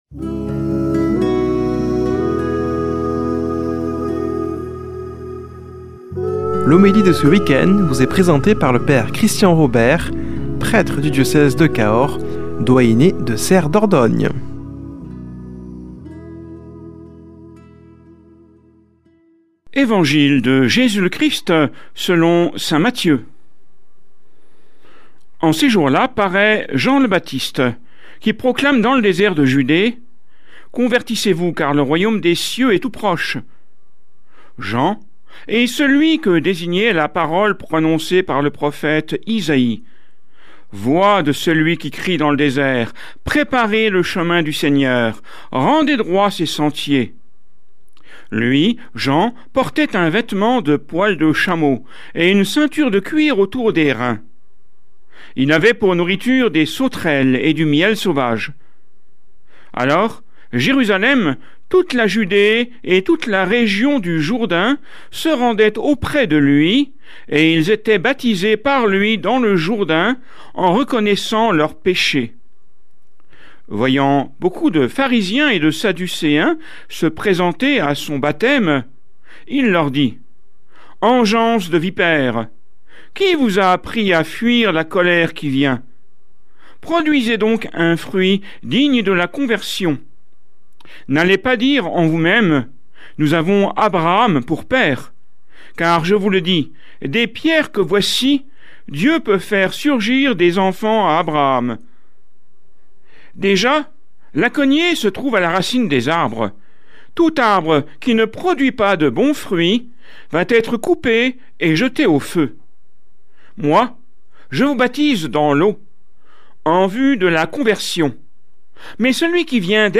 Homélie du 06 déc.